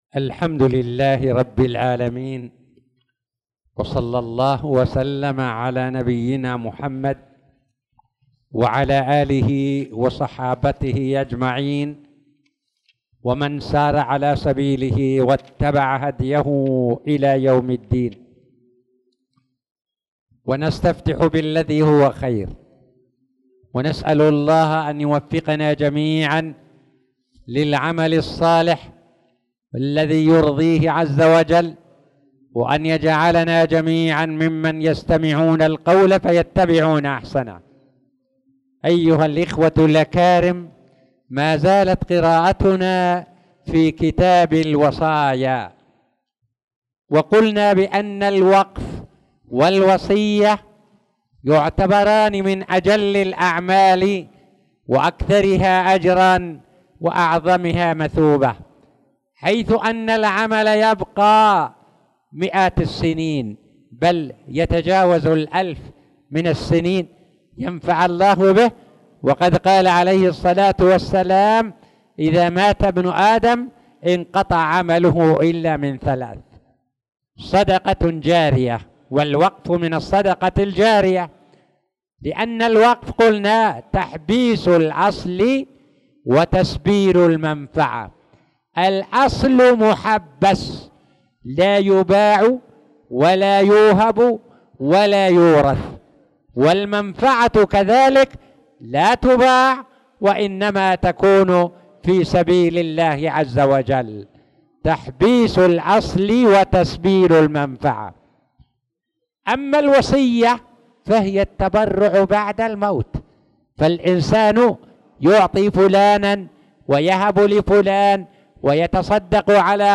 تاريخ النشر ٤ شعبان ١٤٣٧ هـ المكان: المسجد الحرام الشيخ